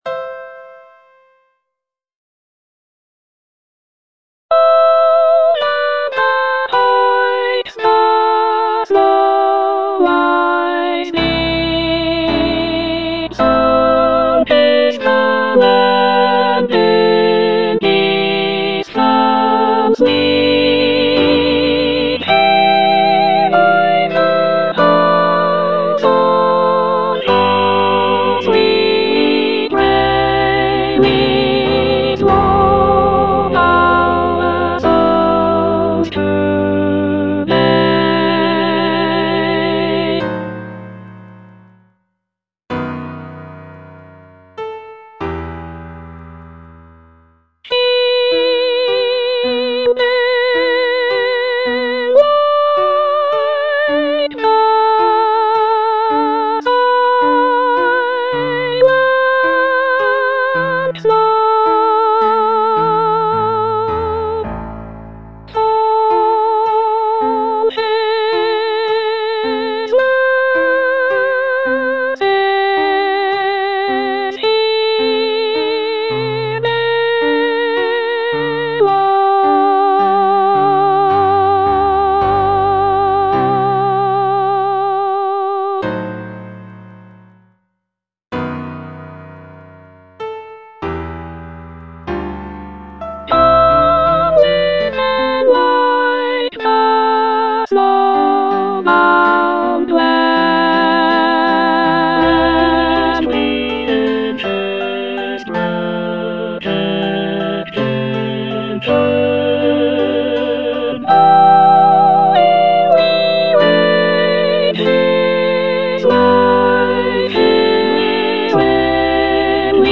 E. ELGAR - FROM THE BAVARIAN HIGHLANDS Aspiration - Soprano (Emphasised voice and other voices) Ads stop: auto-stop
The music captures the essence of the picturesque landscapes and folk traditions of the area, with lively melodies and lush harmonies.